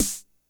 snare04.wav